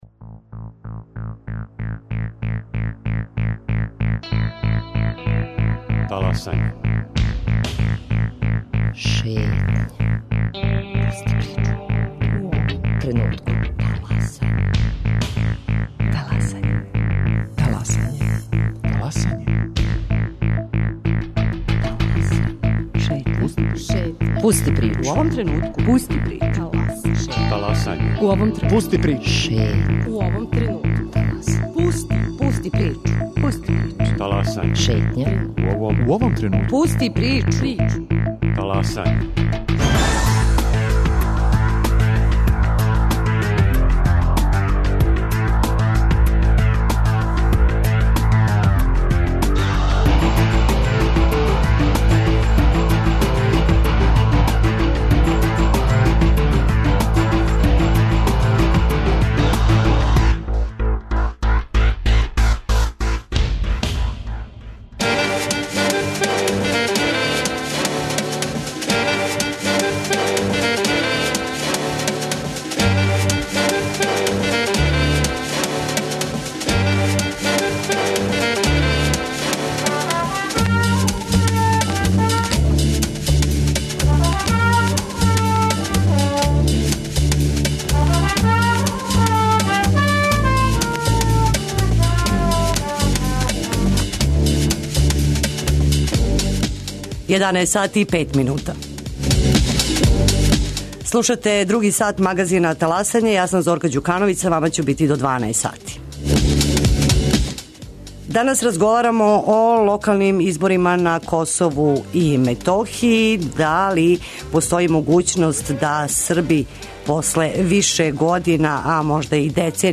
Има ли простора за договор или су поделе међу Србима неизбежне, питамо генералног секретара Владе Србије и шефа београдског тима за примену бриселског споразума Вељка Одаловића.